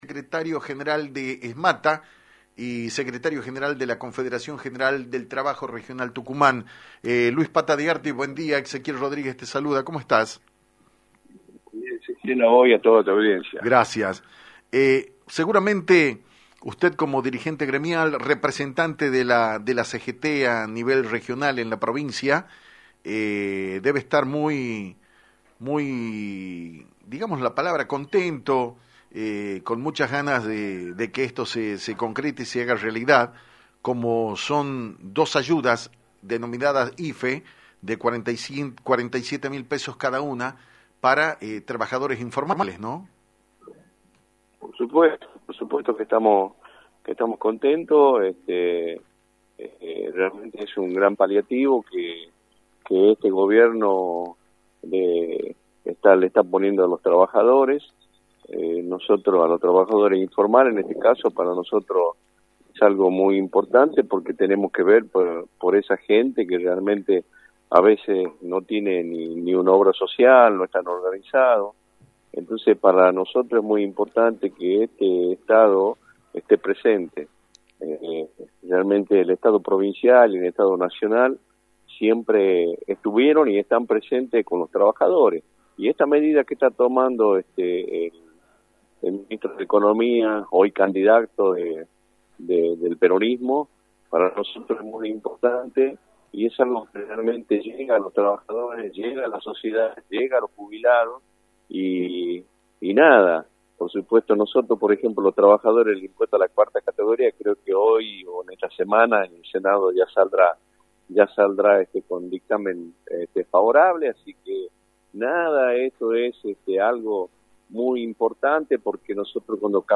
En diálogo exclusivo con Actualidad en Metro